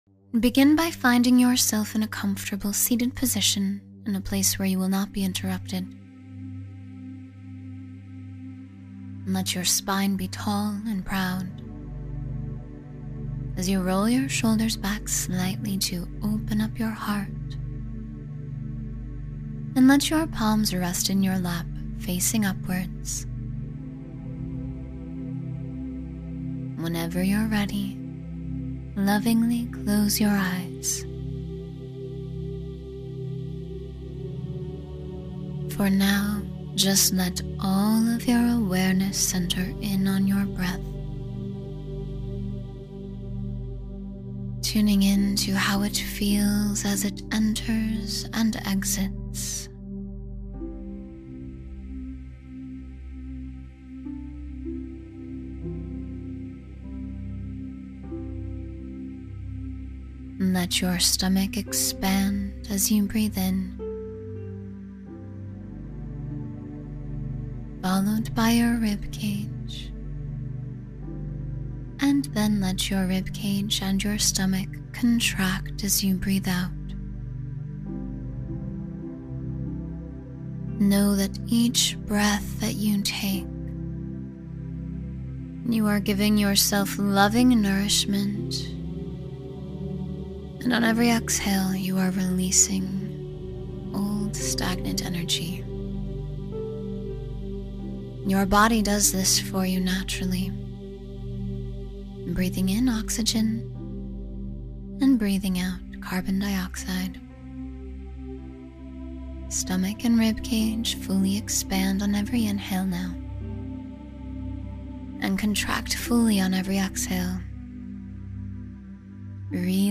Feel Amazing and Empowered — A 10-Minute Guided Meditation